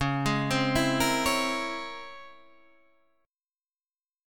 Listen to C#mM13 strummed